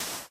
sand1.ogg